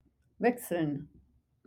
to exchange wechseln (WECH-seln)